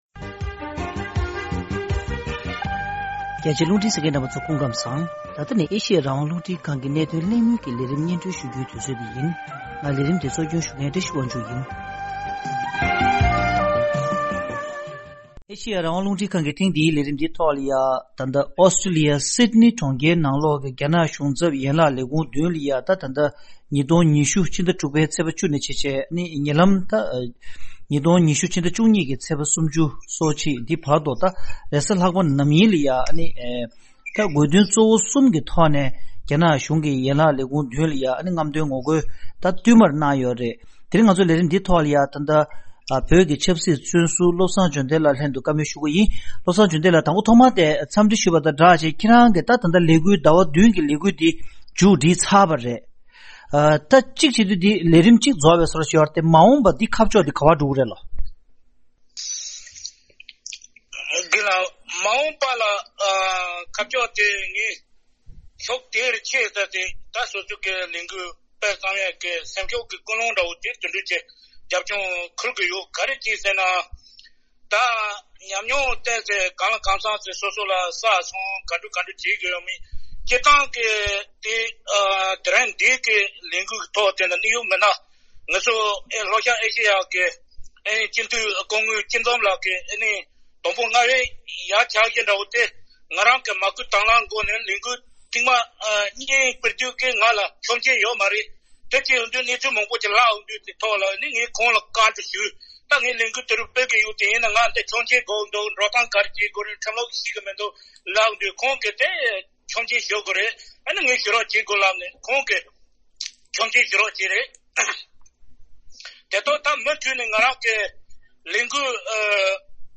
ད་ཐེངས་ཀྱི་གནད་དོན་གླེང་མོལ་གྱི་ལས་རིམ་ནང་།